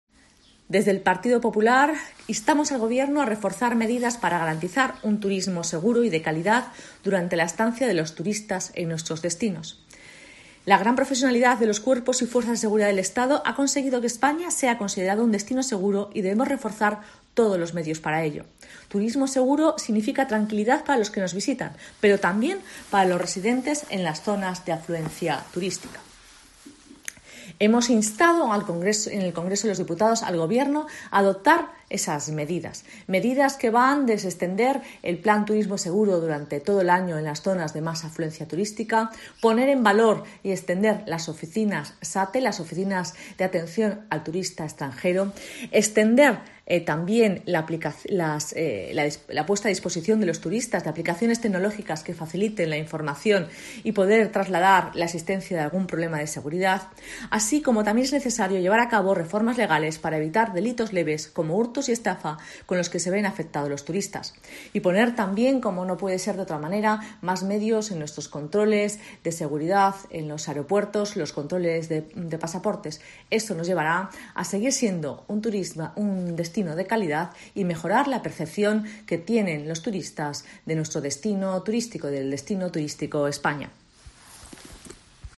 Isabel Borrego, diputada nacional del PPRM